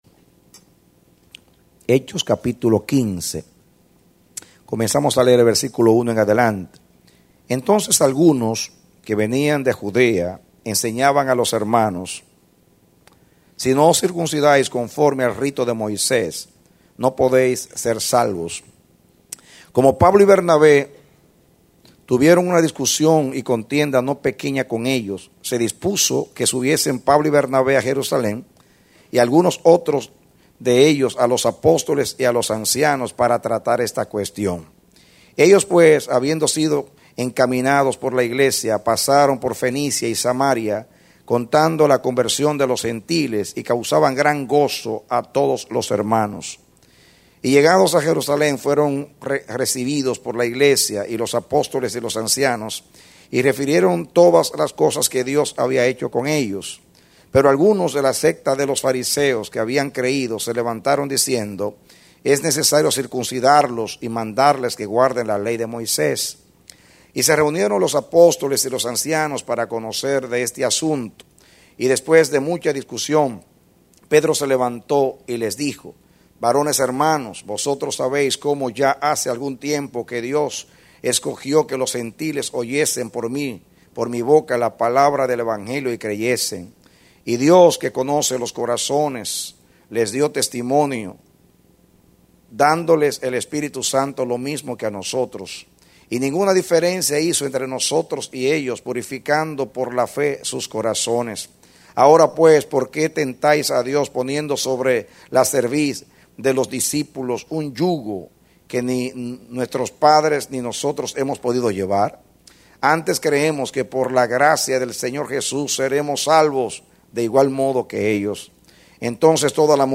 Mensaje: “La Iglesia Saludable # 19”